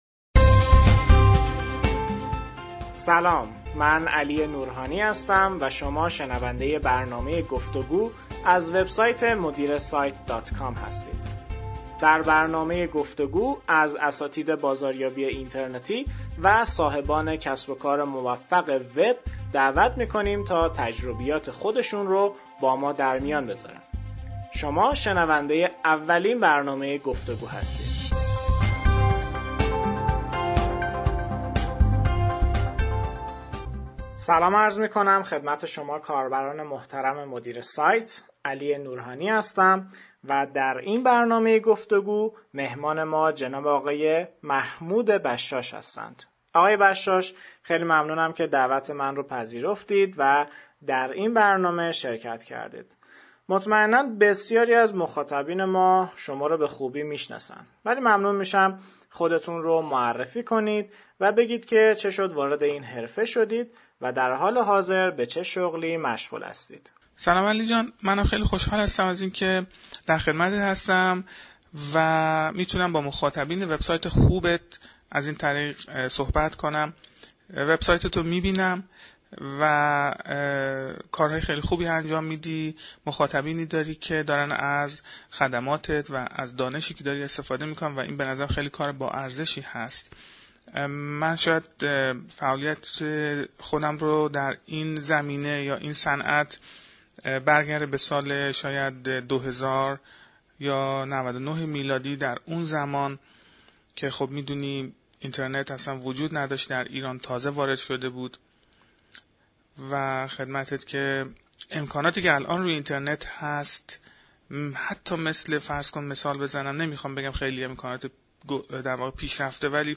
گفتگو